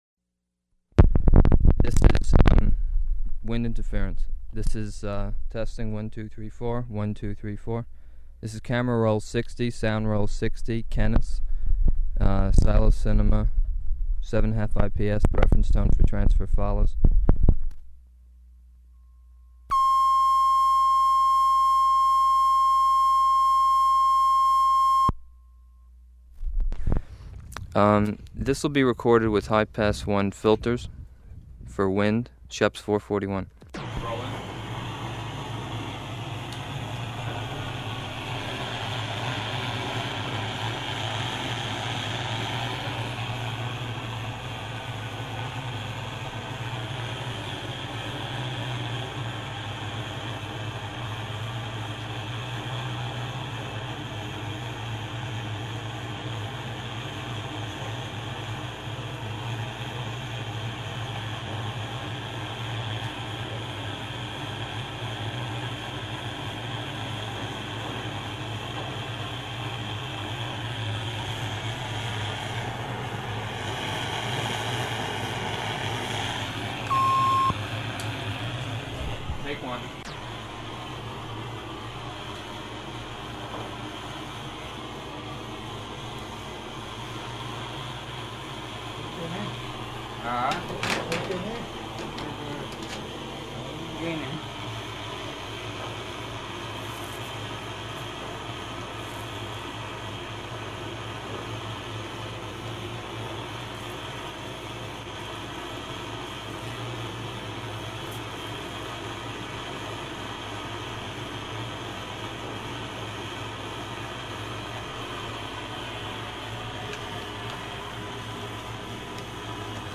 Format 1 sound tape reel (Scotch 3M 208 polyester) : analog ; 7 1/2 ips, full track, mono.
Chelsea (inhabited place) Vermont (state)